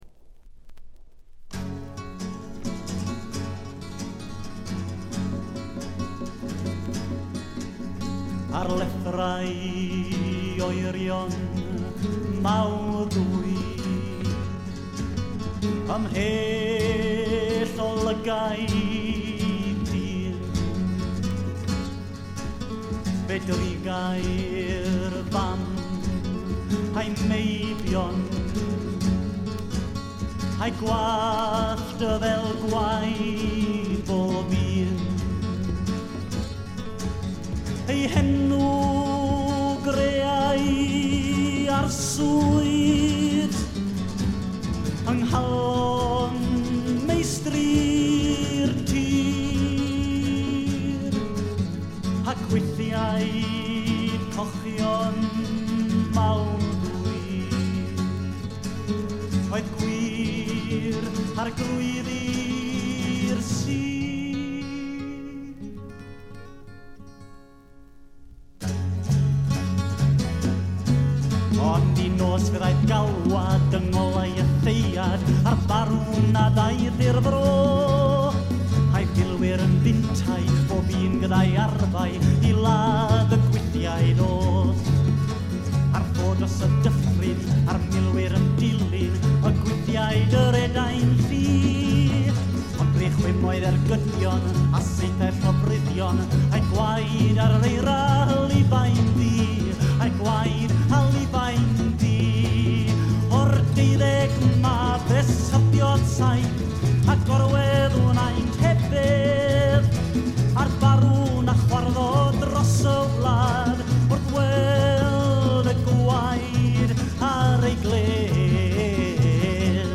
内容はフォーク、アシッド、ポップ、ロックと様々な顔を見せる七変化タイプ。
試聴曲は現品からの取り込み音源です。